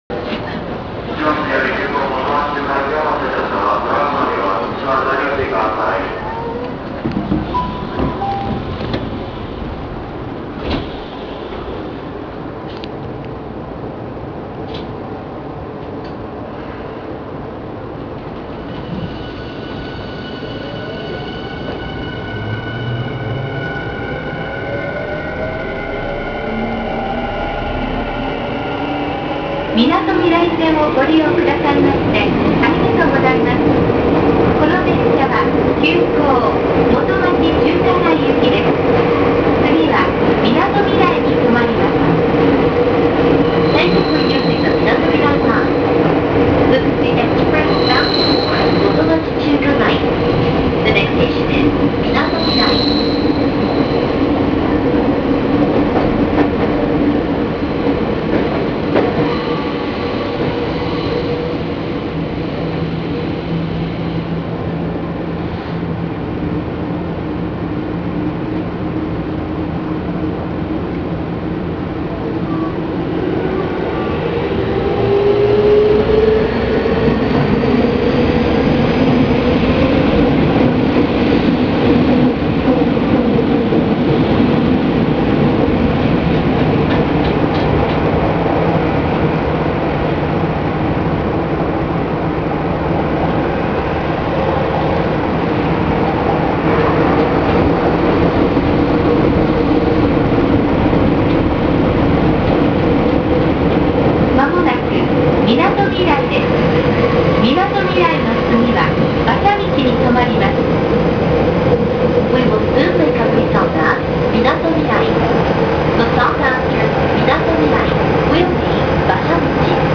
・5000系走行音
【みなとみらい線】横浜→みなとみらい（2分43秒：890KB）
5000系・5050系(後期の編成を除く)・横浜高速Y500系共通の走行音。出発時に微妙に音が下がりり、停車時は微妙に音が上がります。
とにかく音量が大きいので聞きごたえはあります。